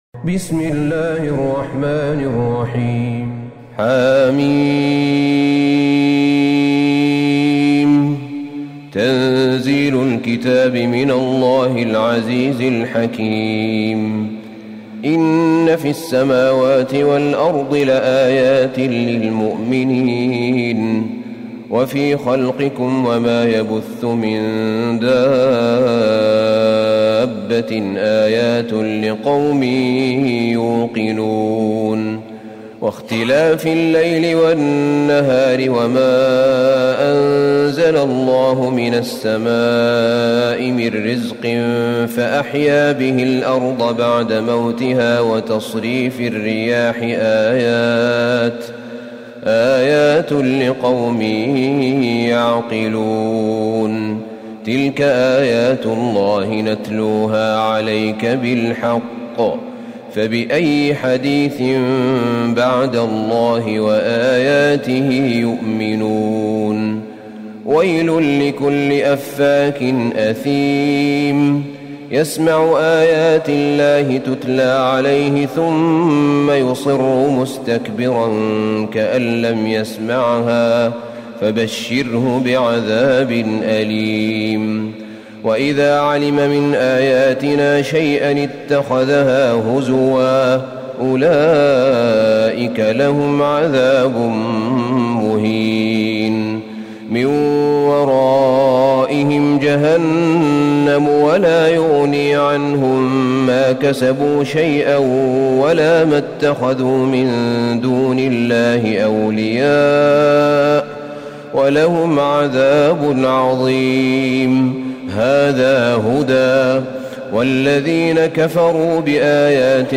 سورة الجاثية Surat Al-Jathiyah > مصحف الشيخ أحمد بن طالب بن حميد من الحرم النبوي > المصحف - تلاوات الحرمين